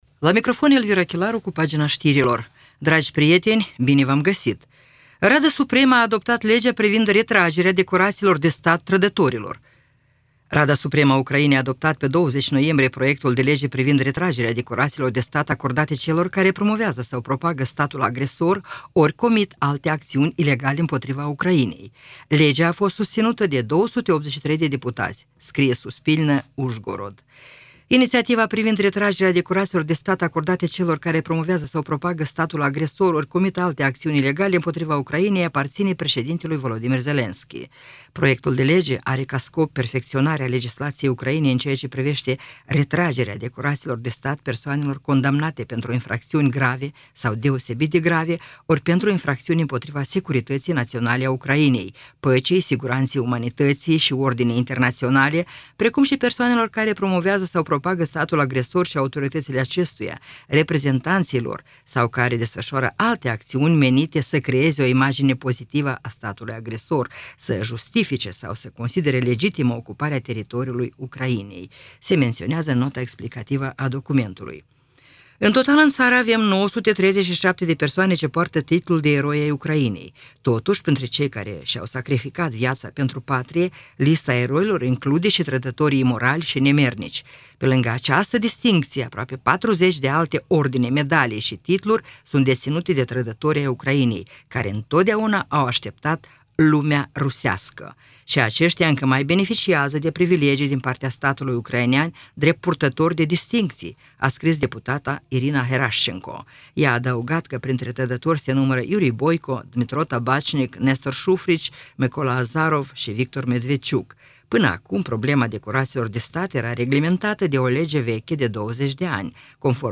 Știri Radio Ujgorod – 21.11.2024
Știri de la Radio Ujgorod.